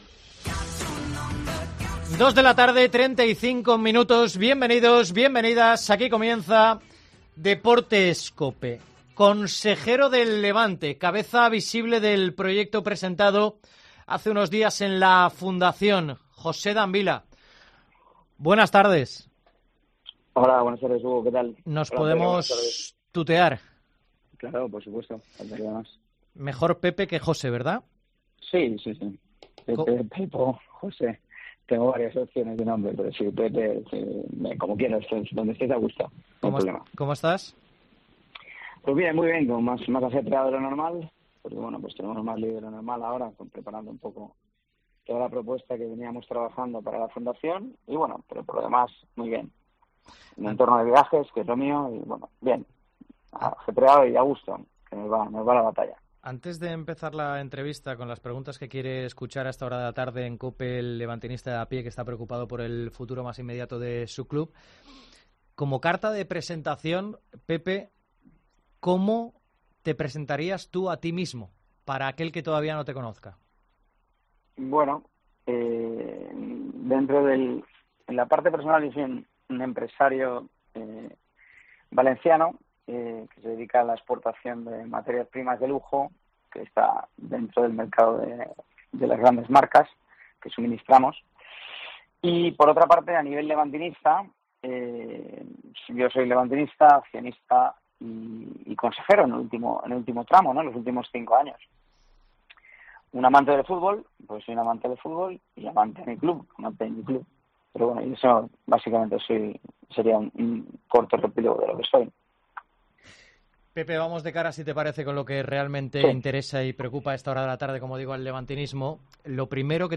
Entrevista en COPE